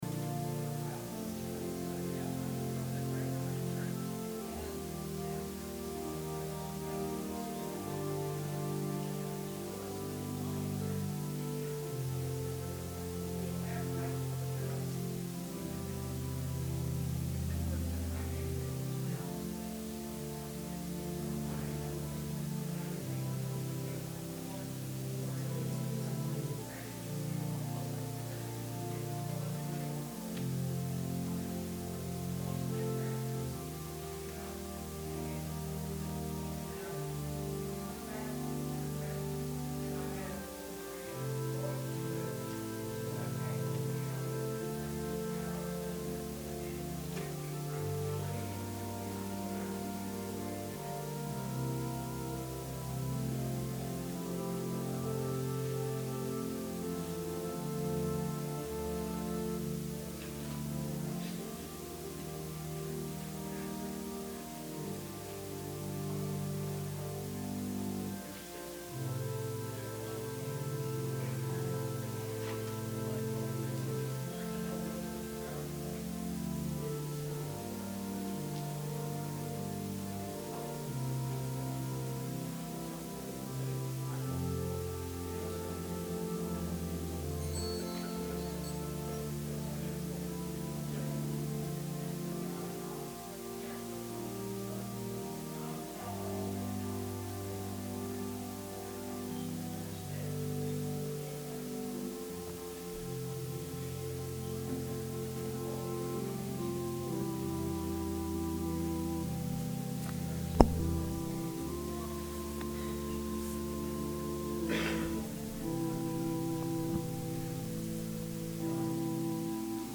Sermon – February 9, 2020
advent-sermon-february-9-2020.mp3